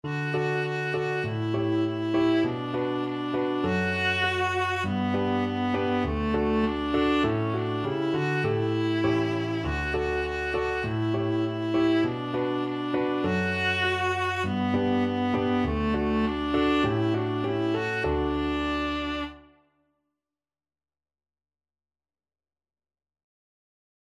Christmas Christmas Viola Sheet Music Jolly Old Saint Nicholas
Viola
D major (Sounding Pitch) (View more D major Music for Viola )
Moderato
2/4 (View more 2/4 Music)